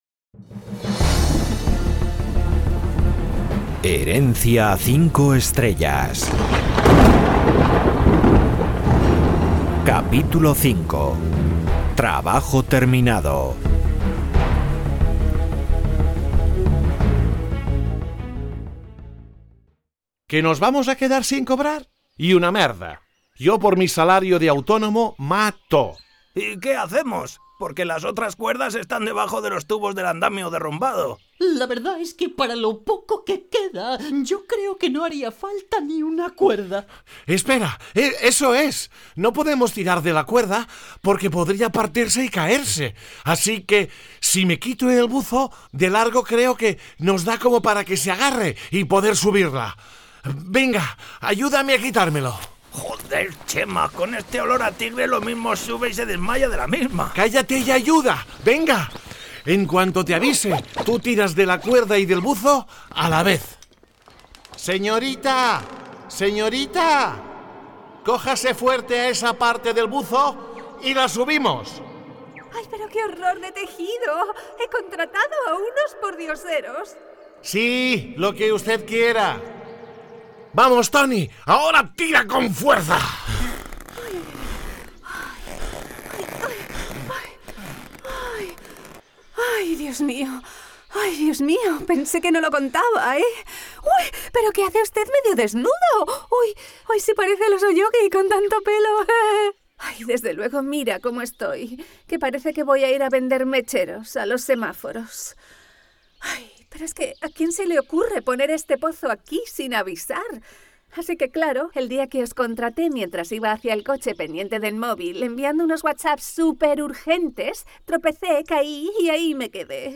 Último capítulo de la serie de audioteatro "Herencia 5 Estrellas".